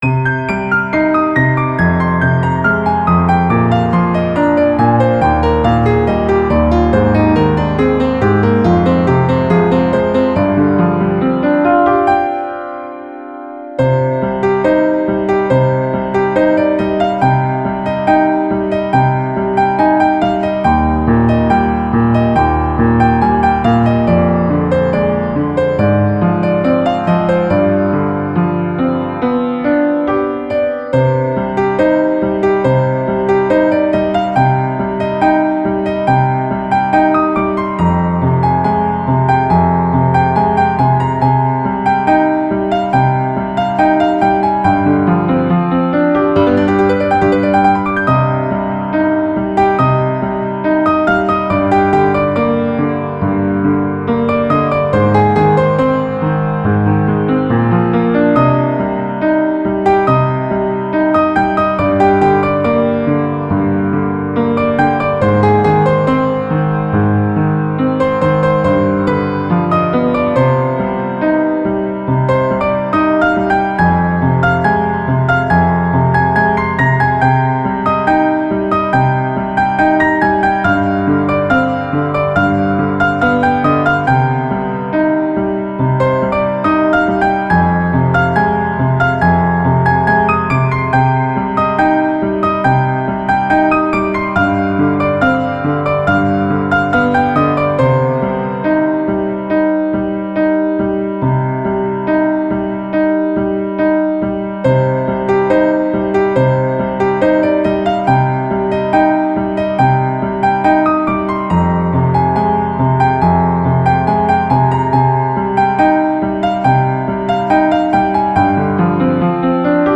موسیقی کلاسیک پیانو
بهترین آهنگ های بی کلام